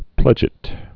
(plĕjĭt)